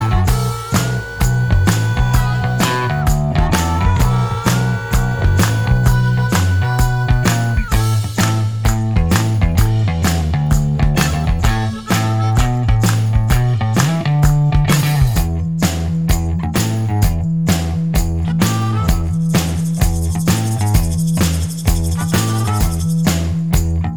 Minus Guitars Pop (1980s) 4:09 Buy £1.50